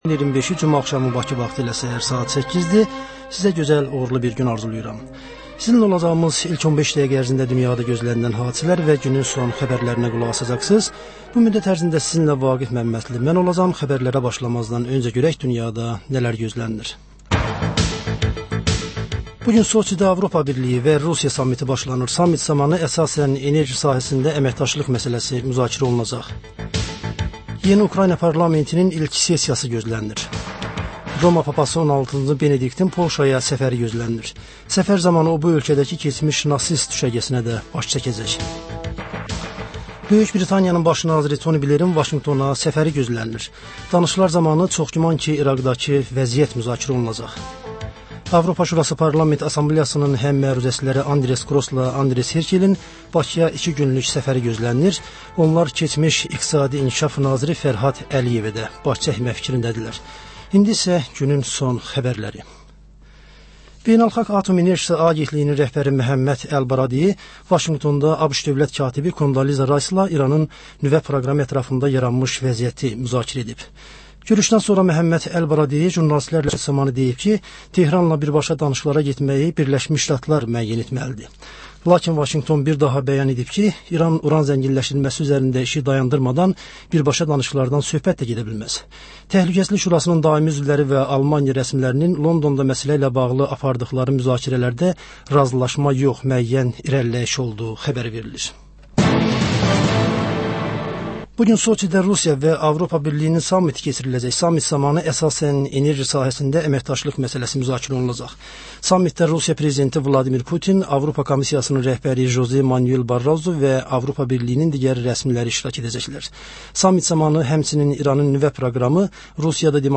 Bugün nələr gözlənir, nələr baş verib? Xəbər, reportaj, müsahibə.